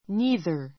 neither níːðər ニ ーざ ｜ náiðə ナ イざ 形容詞 代名詞 （2つのうちの） どちら（の～）も～ない ⦣ 次に名詞を伴 ともな えば形容詞, 単独なら代名詞.